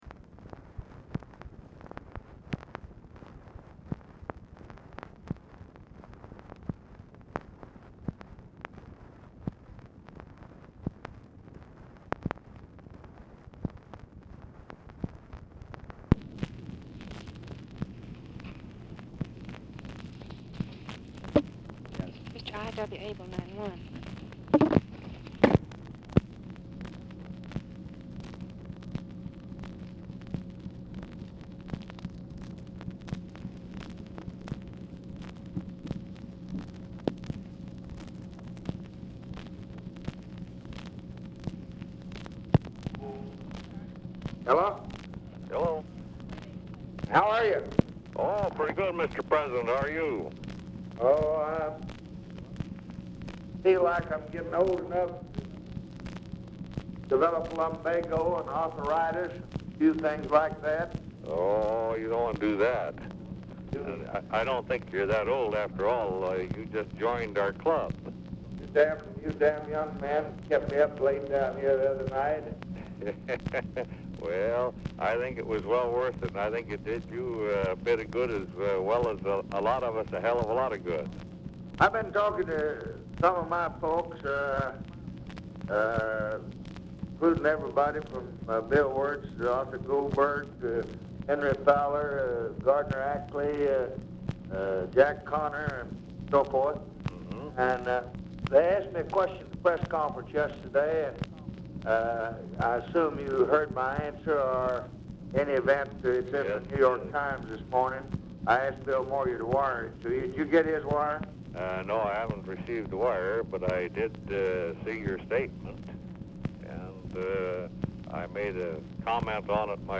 Telephone conversation # 8629, sound recording, LBJ and I. W. ABEL, 8/26/1965, 11:55AM | Discover LBJ
ABEL ON HOLD 0:45; UNIDENTIFIED MALE (JOSEPH CALIFANO?) ANSWERS TELEPHONE
Format Dictation belt
Location Of Speaker 1 Oval Office or unknown location
Specific Item Type Telephone conversation